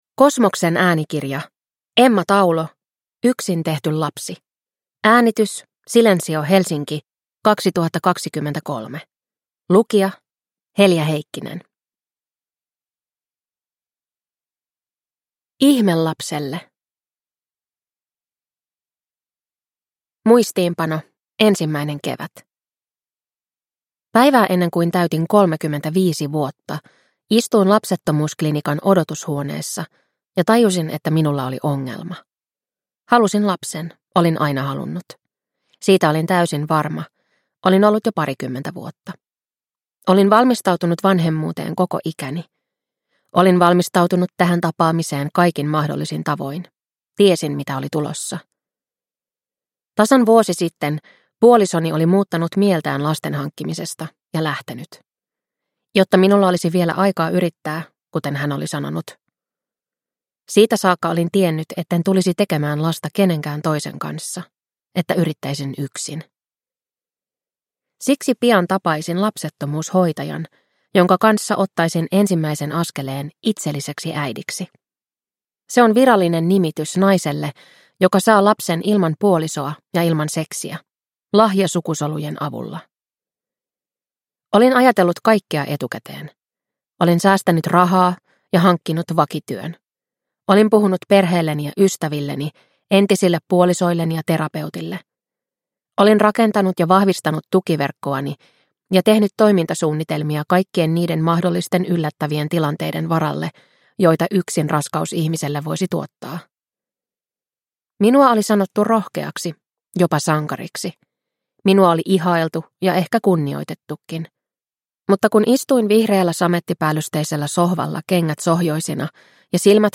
Yksin tehty lapsi – Ljudbok